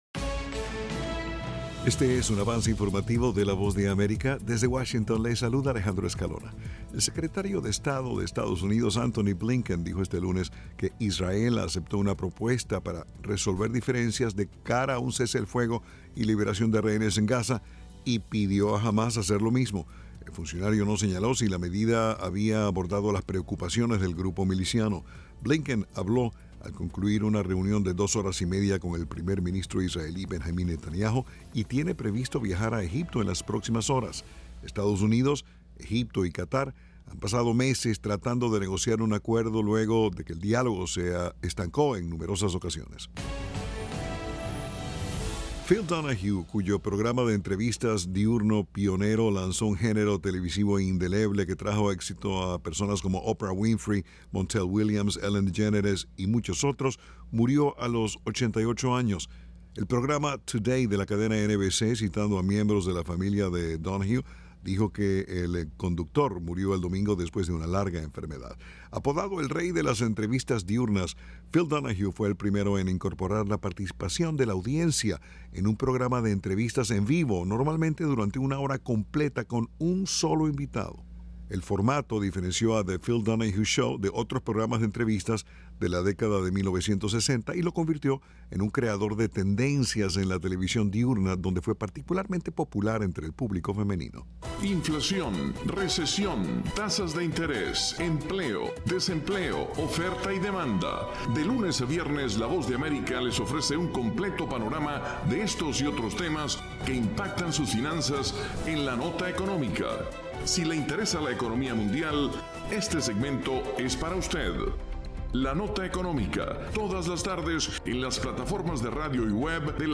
Este es un avance informativo presentado por la Voz de América en Washington.